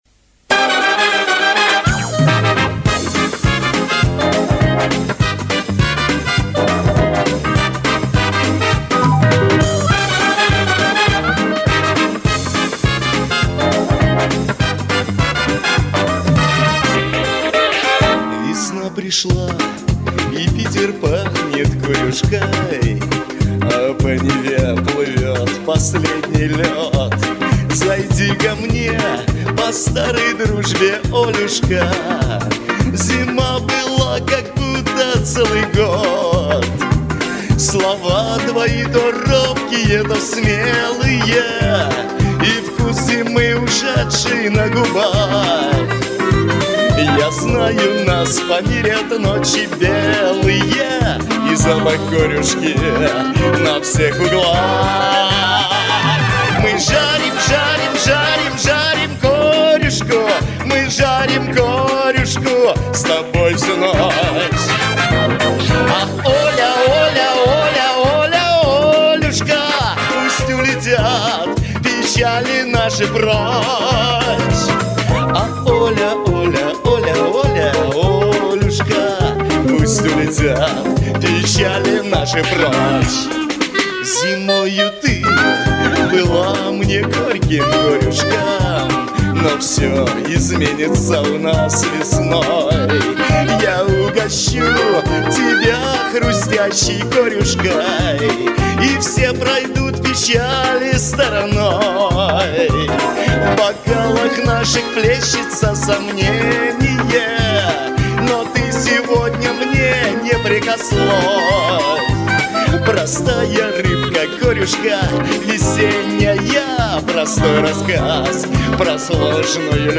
веселая песня из разряда шансона